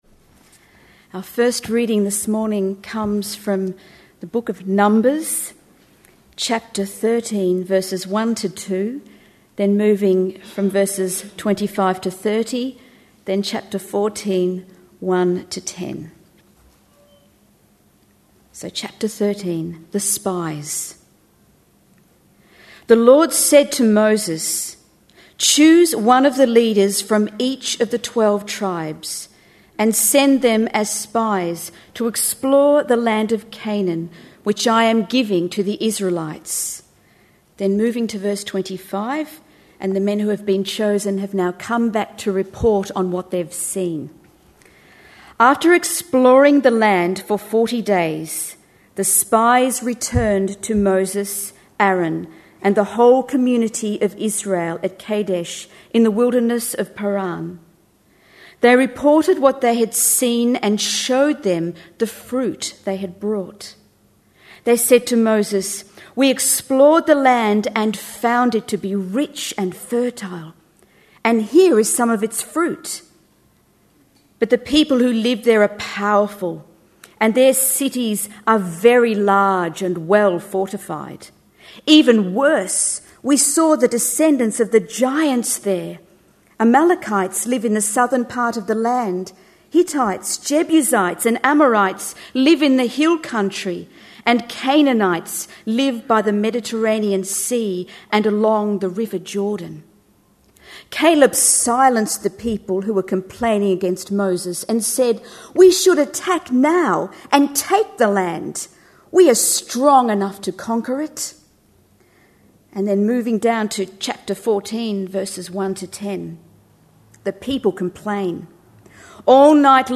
A sermon preached on 15th August, 2010, as part of our Old Testament Couples series.